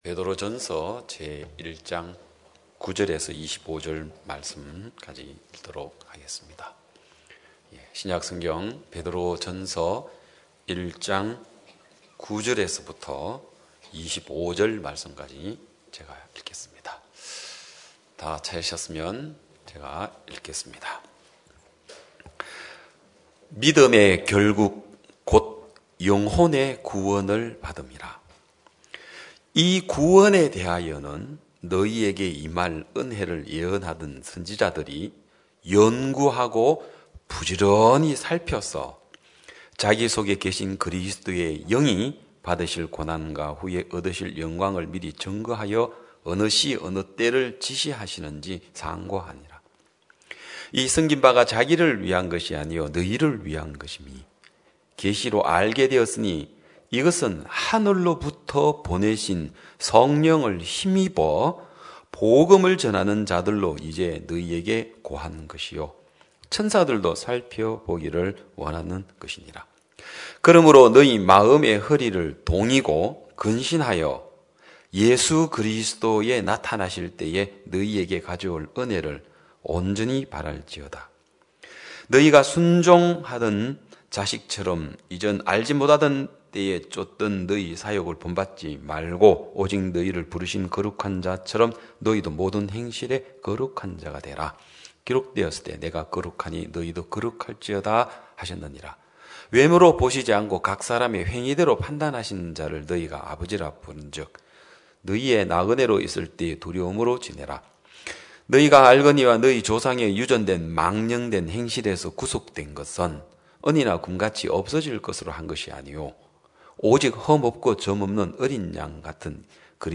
2021년 12월 19일 기쁜소식양천교회 주일오전예배